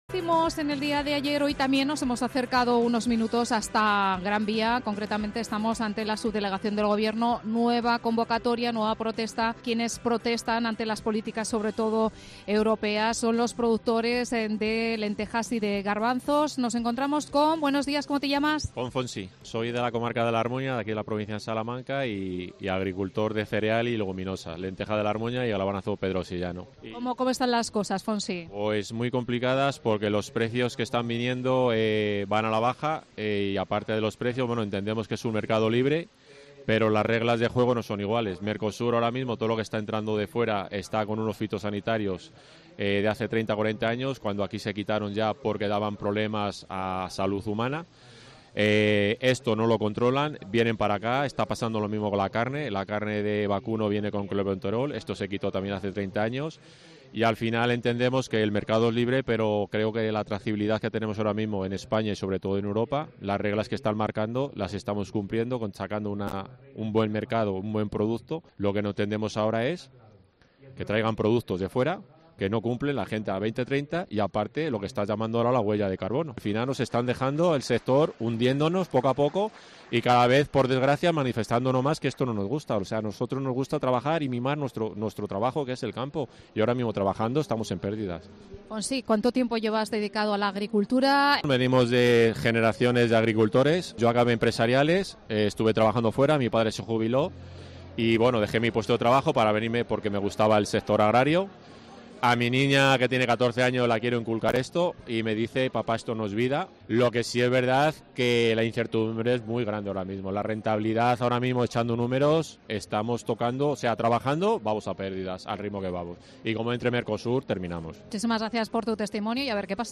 Protestas agrarias en Salamanca